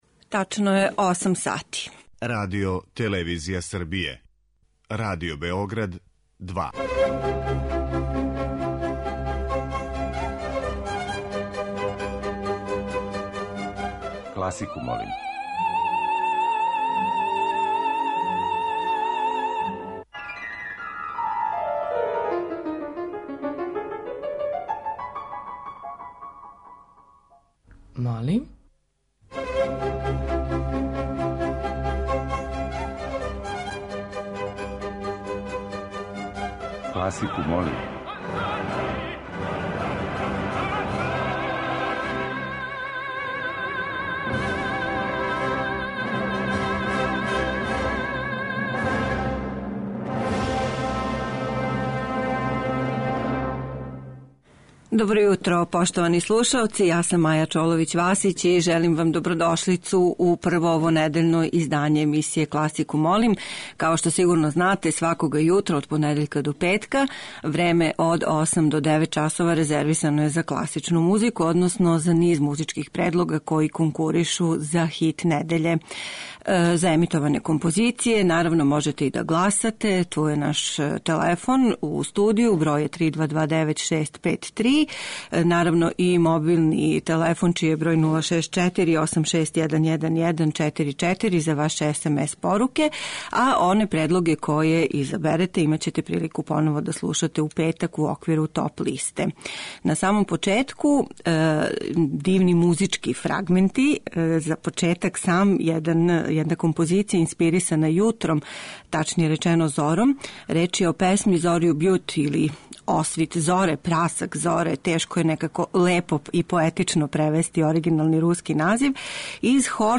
У недељи у којој се у Београду одржава међународни Сајам књига, тема циклуса емисија Класику молим биће програмска дела инспирисана литерераним остварњима, па ће се тако међу изабраним предлозима за хит недеље наћи и оркестарске композиције Штрауса, Берлиоза, Римског Корксакова, Равела, Чајковског и других.